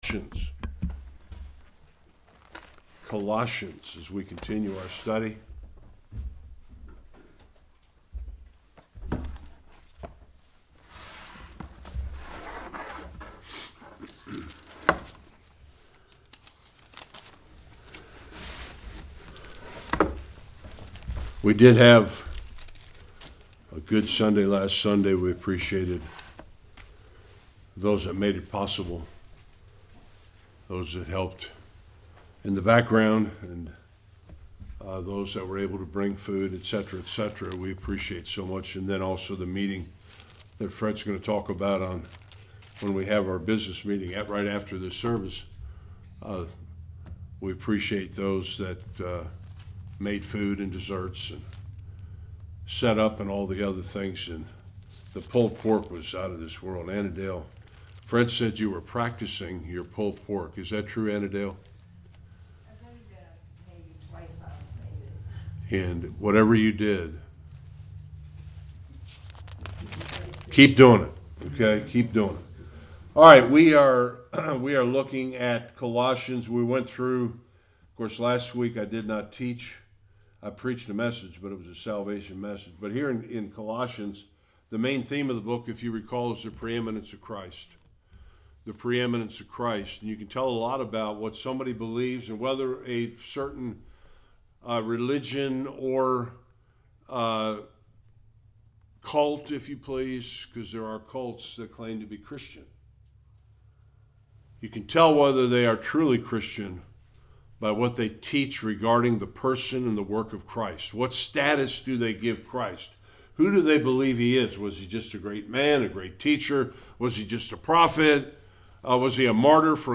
3-6 Service Type: Sunday Worship The Lord Jesus Christ is preeminent in all things.